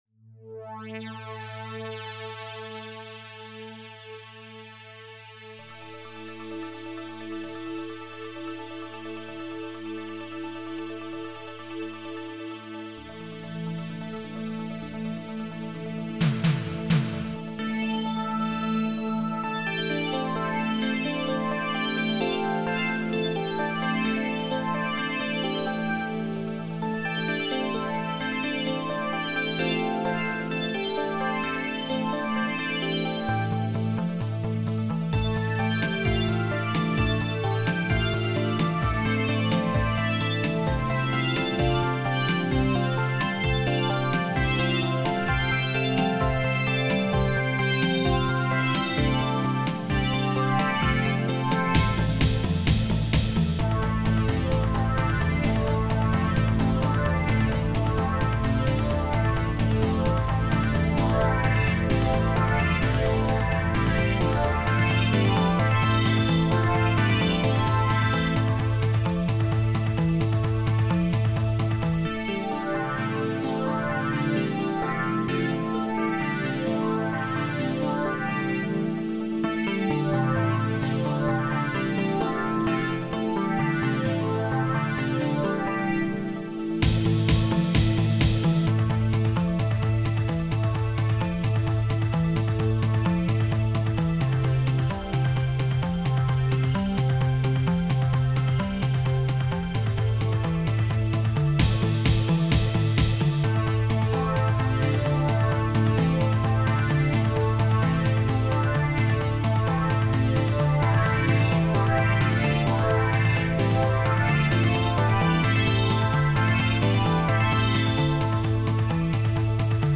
electronic
nostalgic
synthwave